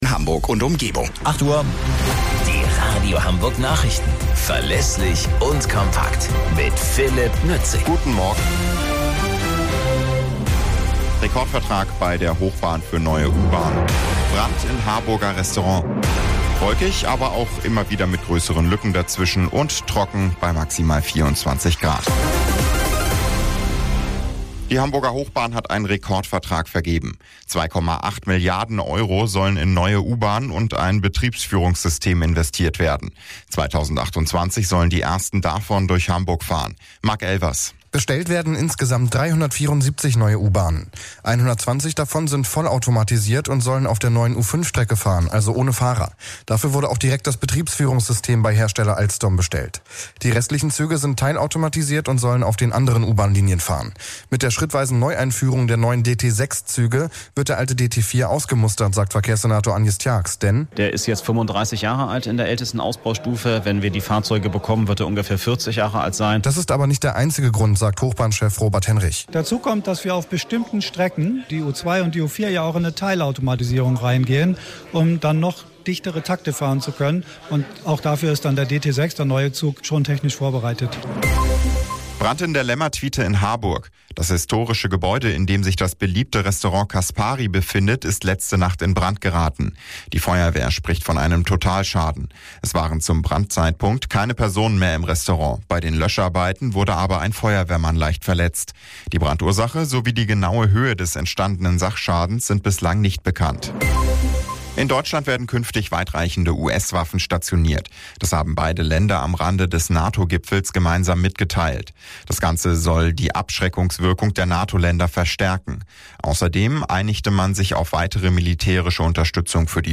Radio Hamburg Nachrichten vom 04.08.2024 um 22 Uhr - 04.08.2024